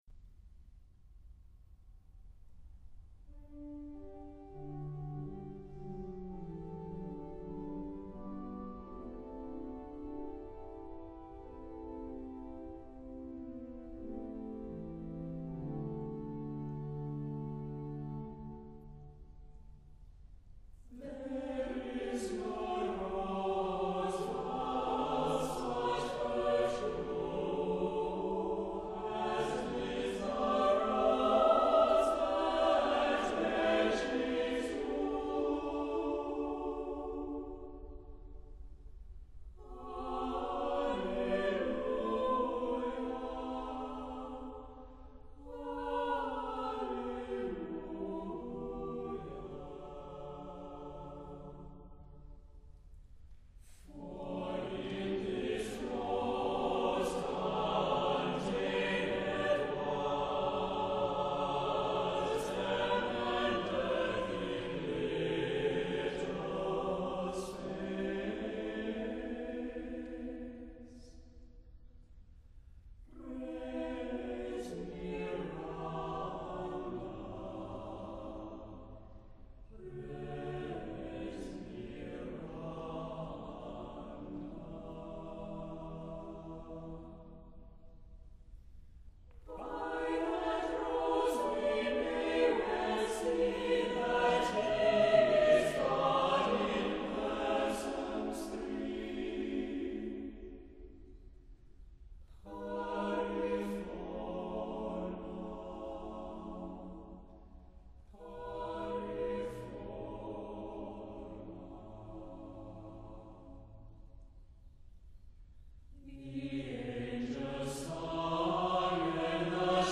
• Music Type: Choral
• Voicing: SATB, Tenor Solo
• Accompaniment: Organ
• Season: Christmas